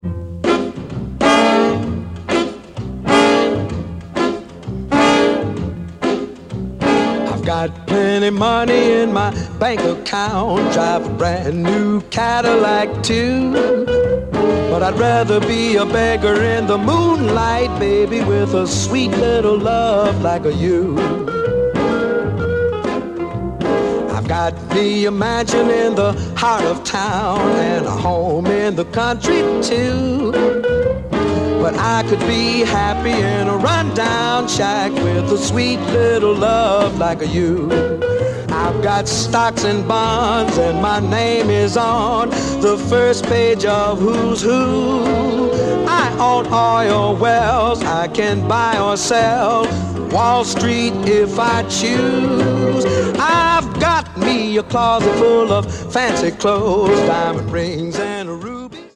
languid, jazzy, finger-clicking version